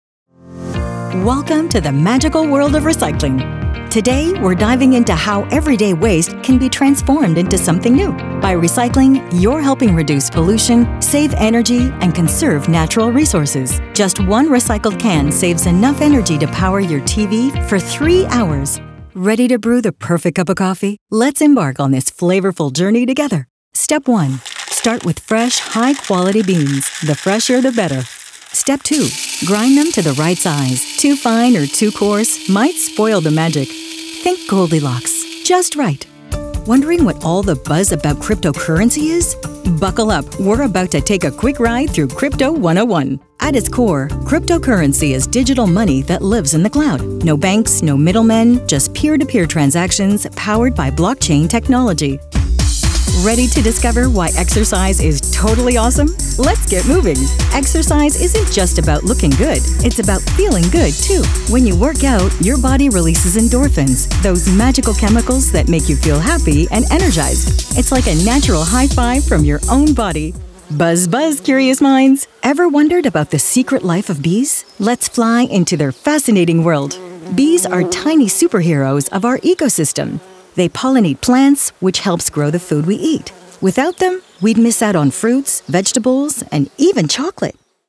Corporate demo (EN)
English - USA and Canada